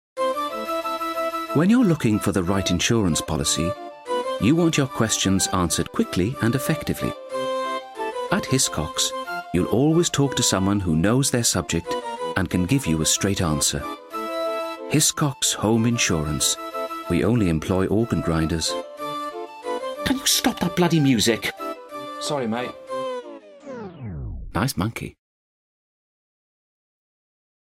Commercial - Hiscox - Cheerful, Warm, Informative